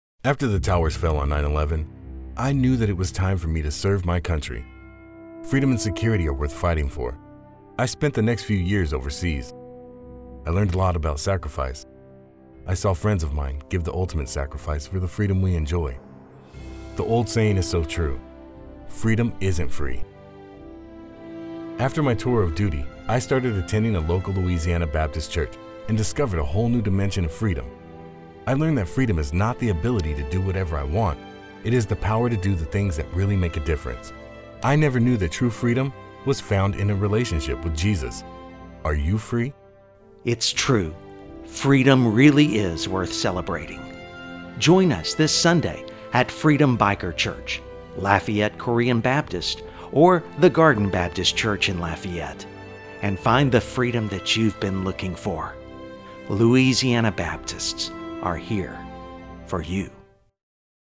Deep rich voice with calm and energetic tones.
Sprechprobe: Industrie (Muttersprache):